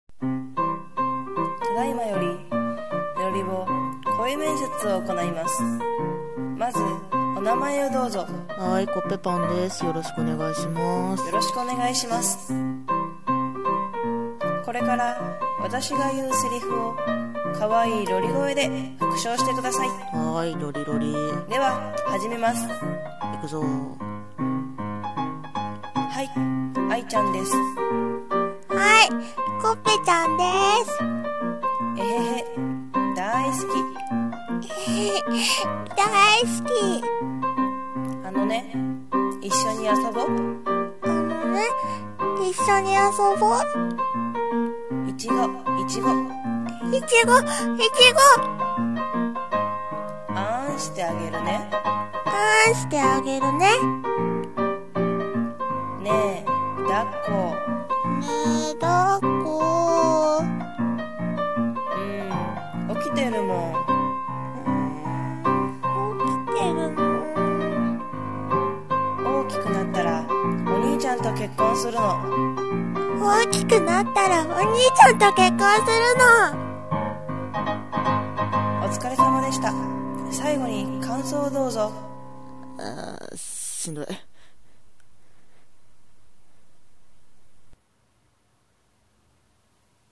ロリボ声面接